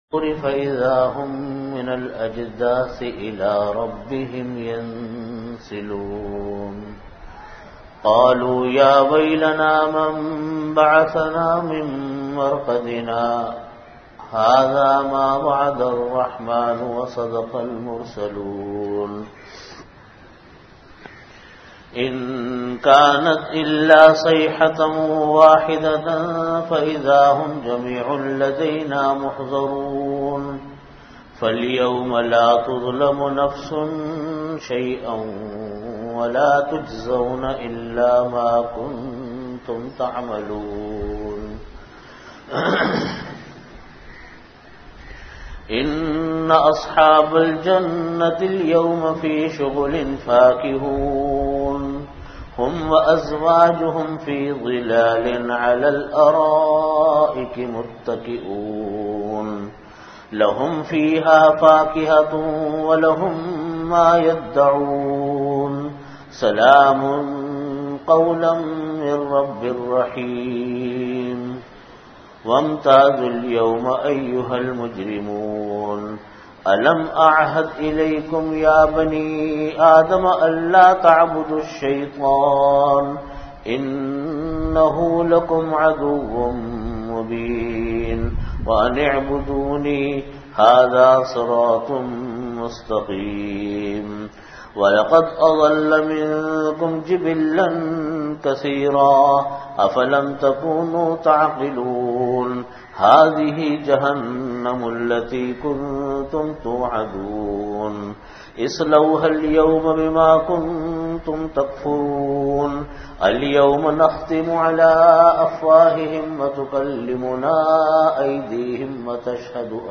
Category: Tafseer
Venue: Jamia Masjid Bait-ul-Mukkaram, Karachi